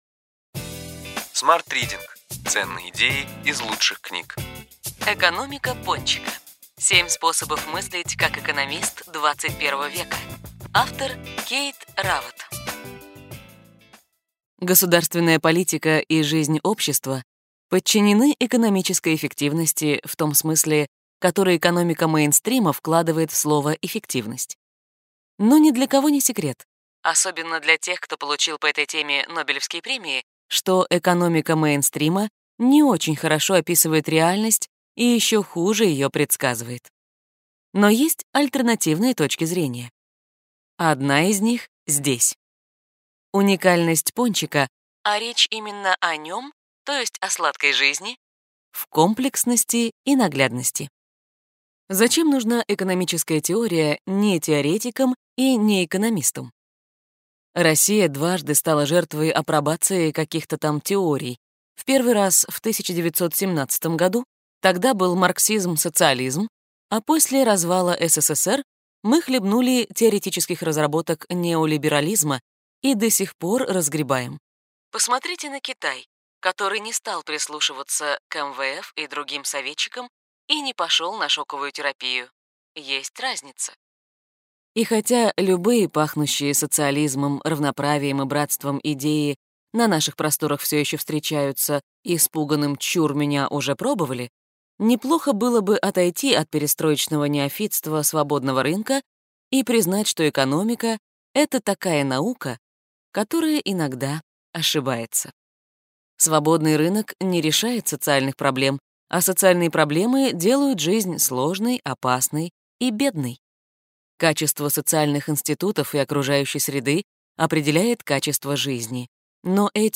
Аудиокнига Ключевые идеи книги: Экономика пончика: семь способов мыслить как экономист XXI века.